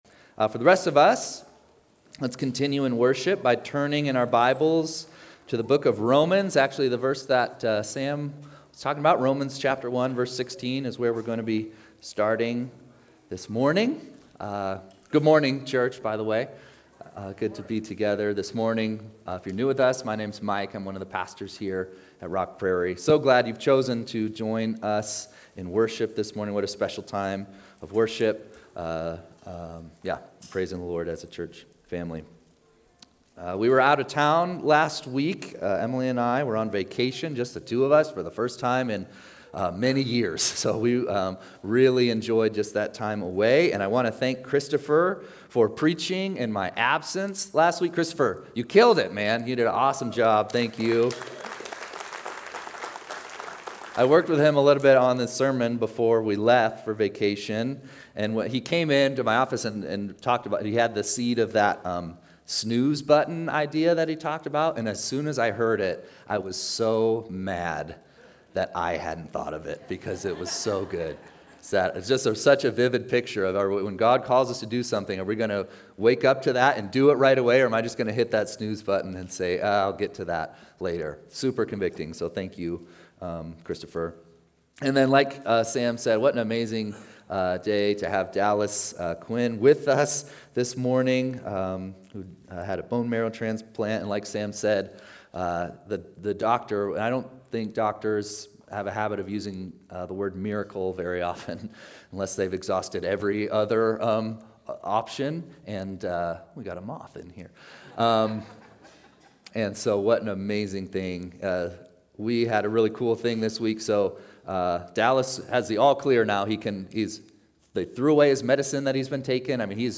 9-21-25-Sunday-Service.mp3